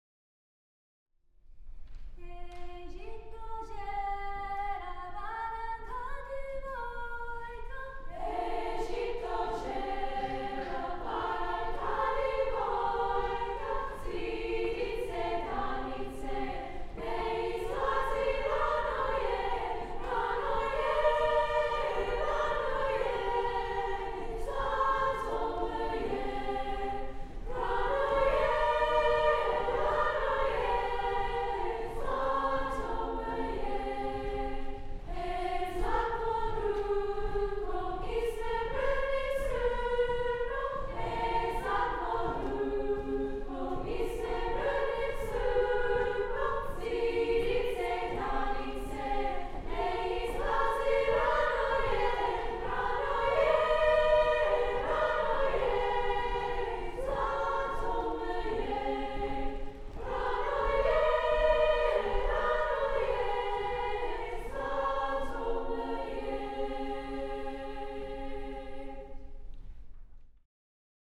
Ej zito zela – trad. Croatian
Croatian-Ej-zito-zela-Claremont-Treble-Singers.mp3